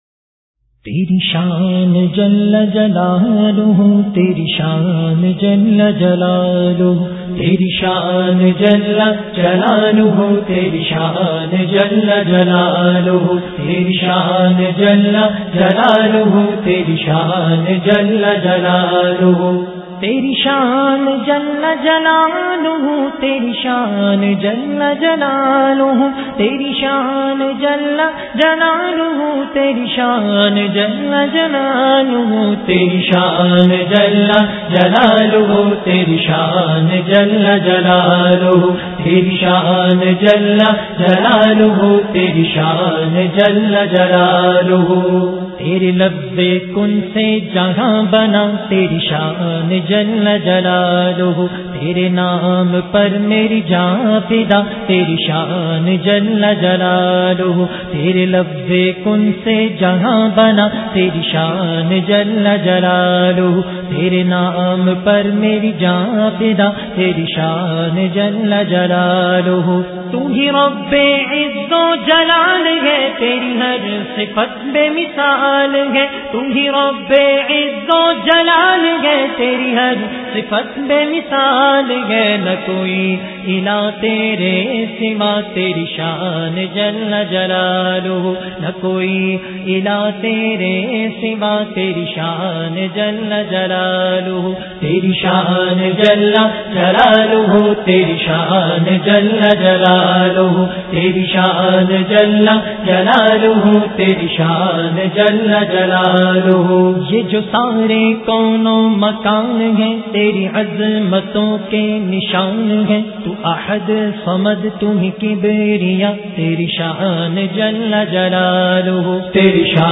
Islamic Qawwalies And Naats